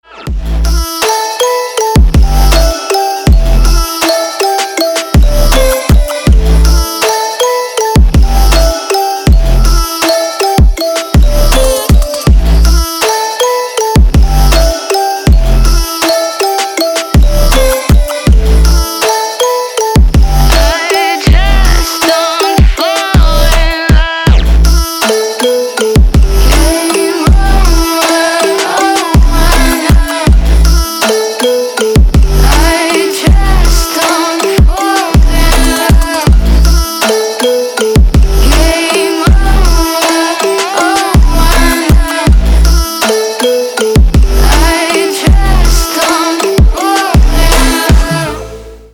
• Песня: Рингтон, нарезка